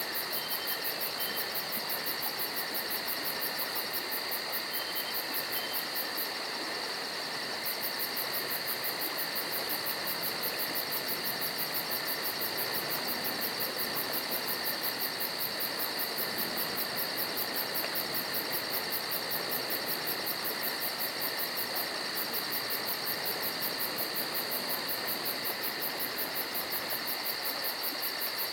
forest.ogg